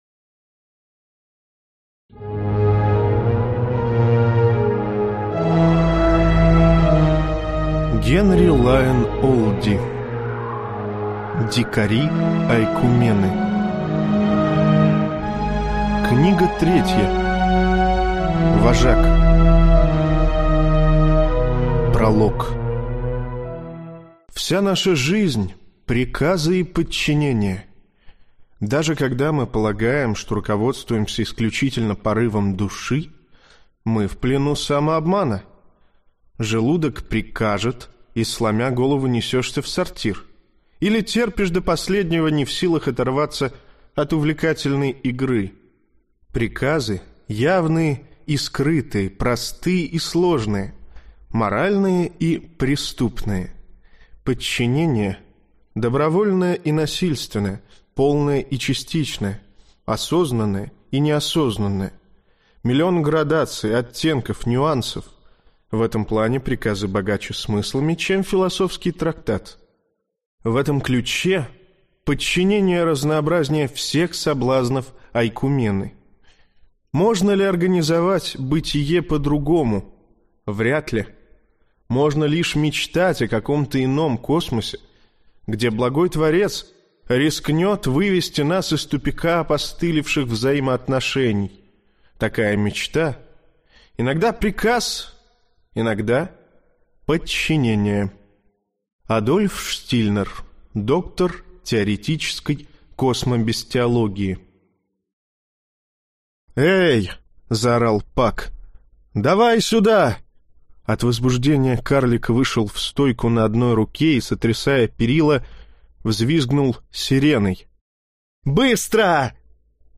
Аудиокнига Вожак | Библиотека аудиокниг
Прослушать и бесплатно скачать фрагмент аудиокниги